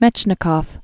(mĕchnĭ-kôf, myĕchnĭ-kəf), Élie Originally Ilya Ilyich Mechnikov 1845-1916.